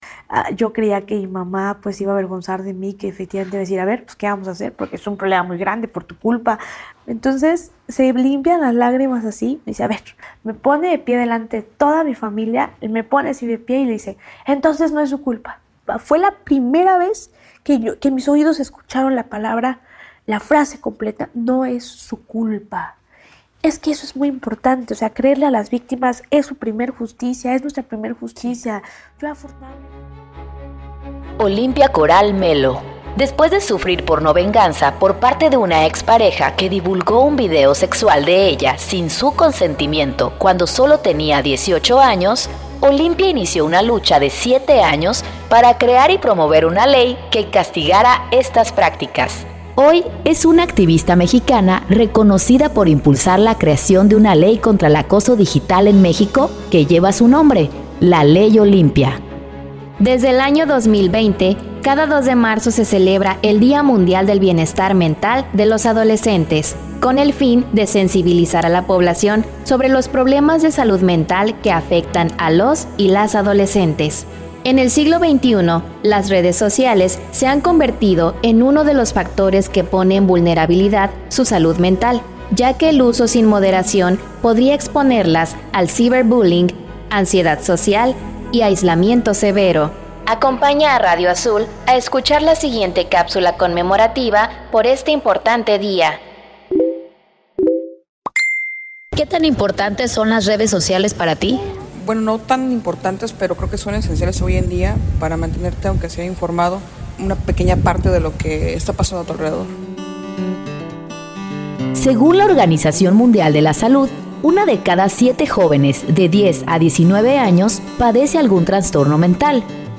Acompaña a Radio azul a escuchar la siguiente cápsula conmemorativa.